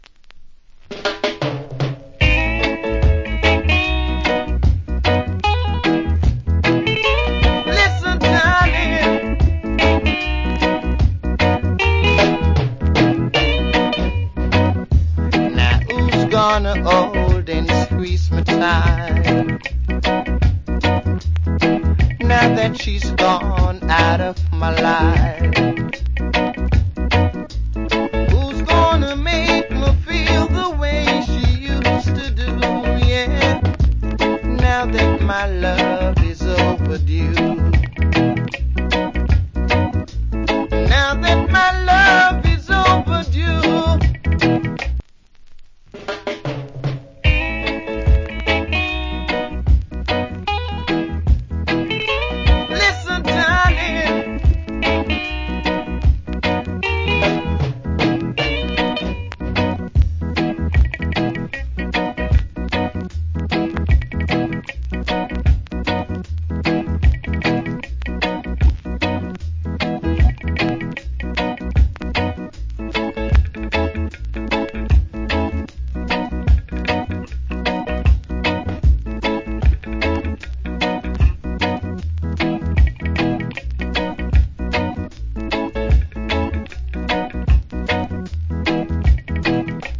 Old Hits Reggae.